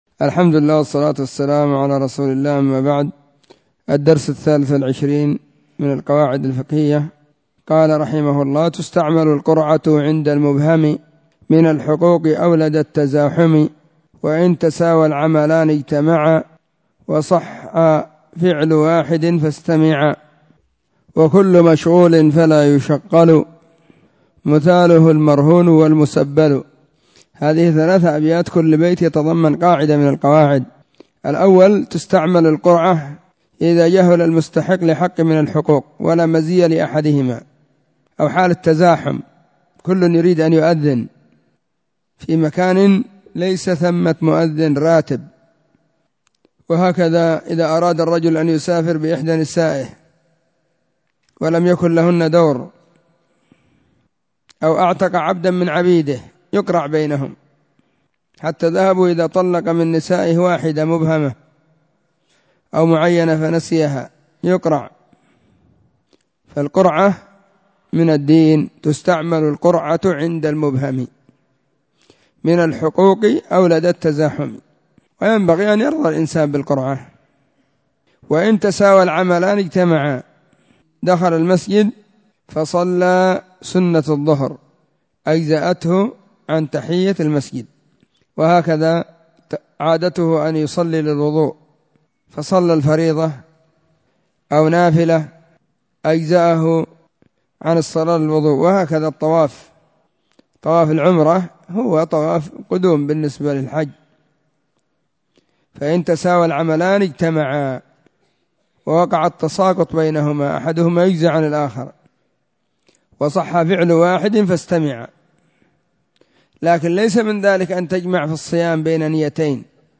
↔ بين مغرب – وعشاء – الدرس – الثاني